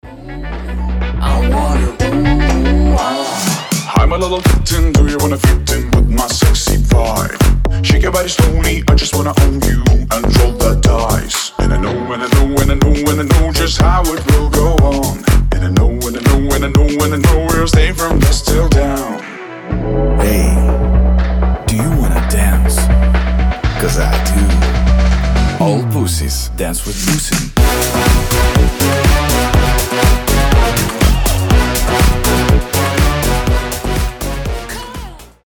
• Качество: 320, Stereo
мужской голос
заводные
Dance Pop
house